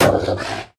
Minecraft Version Minecraft Version 1.21.5 Latest Release | Latest Snapshot 1.21.5 / assets / minecraft / sounds / mob / wolf / angry / hurt2.ogg Compare With Compare With Latest Release | Latest Snapshot
hurt2.ogg